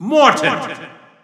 Announcer pronouncing Morton in French.
Morton_French_Announcer_SSBU.wav